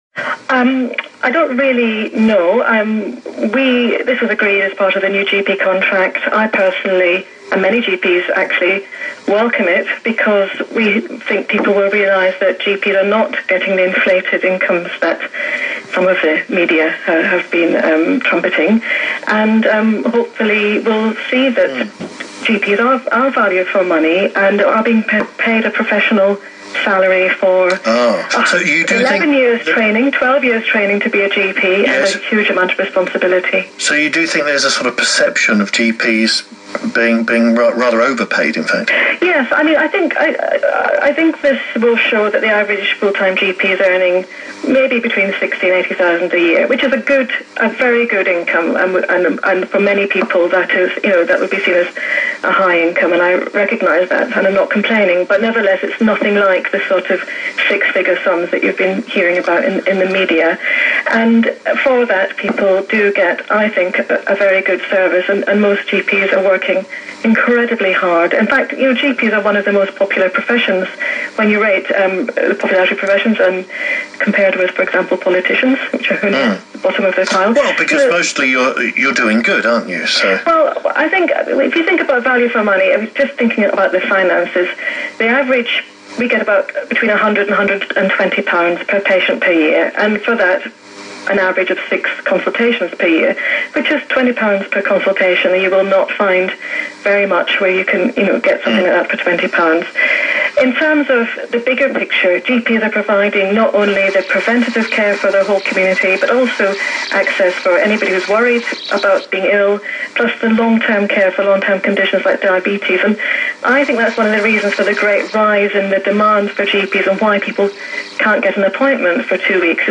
LBC radio